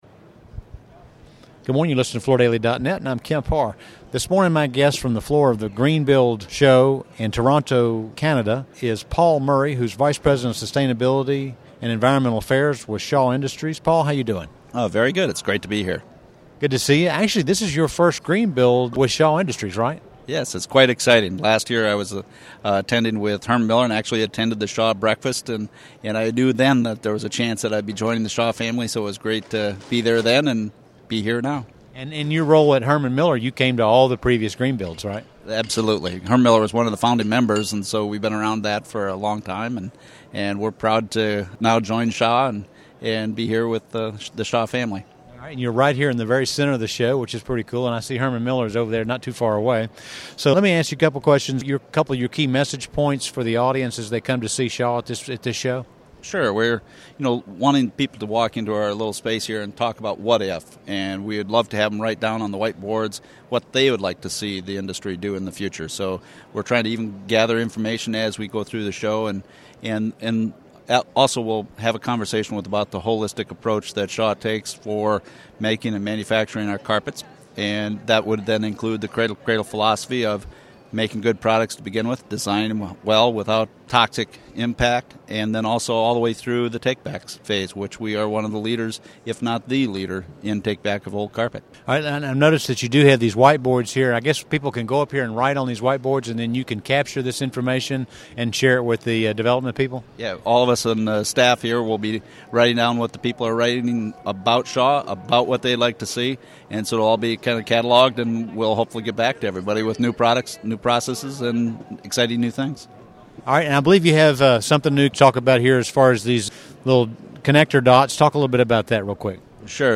Listen to the interview to find out more about Shaw's cradle-to-cradle philosophy for product development, its takeback program, and how it's soliciting sustainability ideas from show attendees.